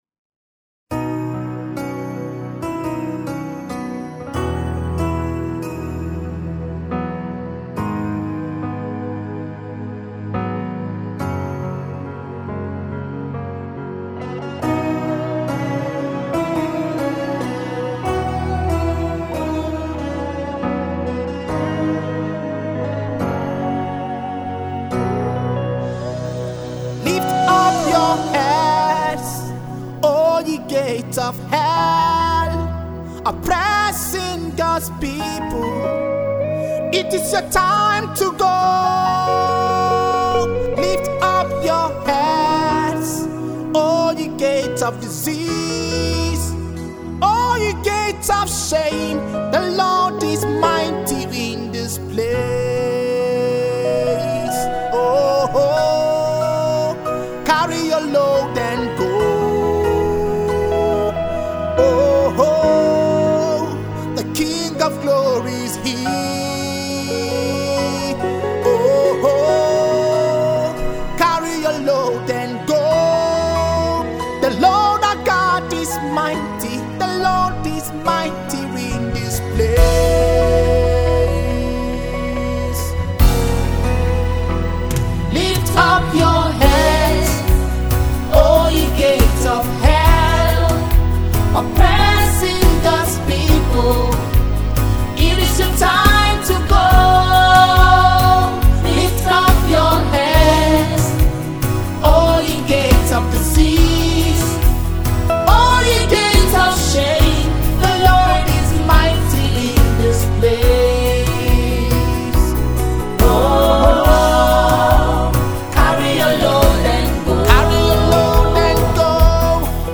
Nigerian Gospel minister
an anointed worship/praise leader